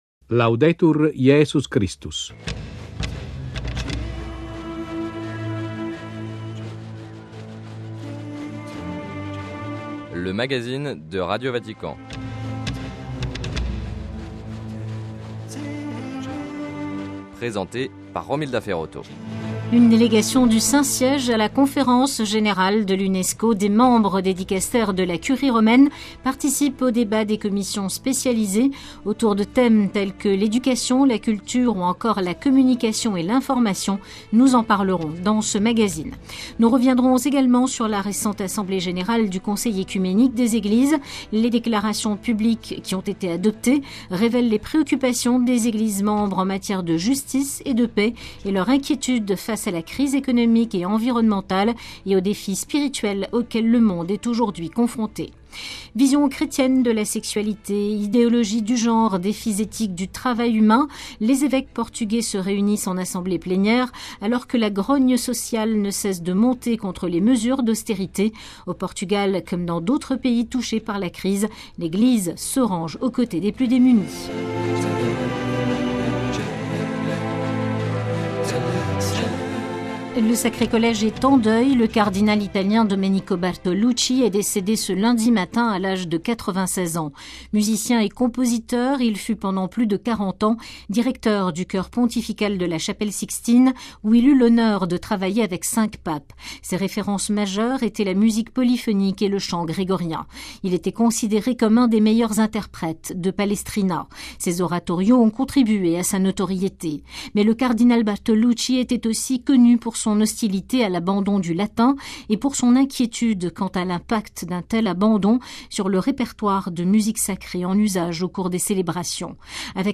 - Don du pape François aux sinistrés du typhon aux Philippines. - Entretien avec Mgr Francesco Follo, observateur permanent du Saint-Siège à l'Unesco.